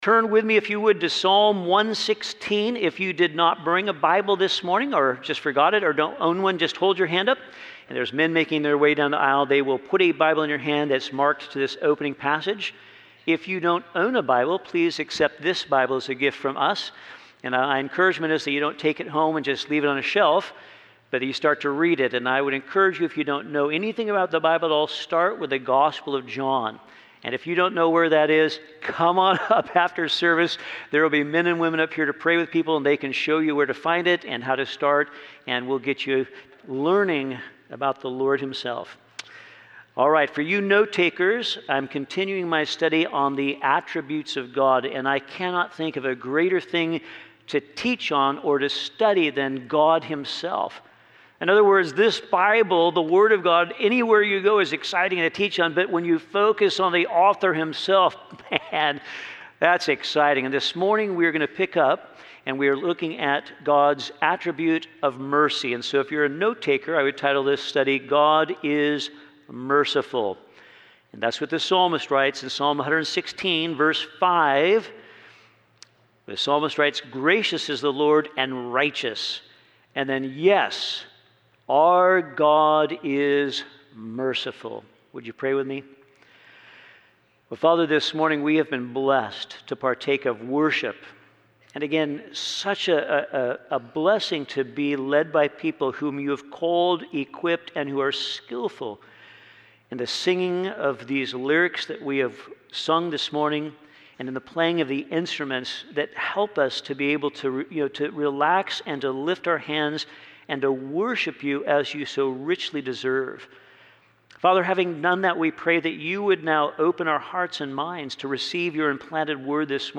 From Series: "Guest Speakers"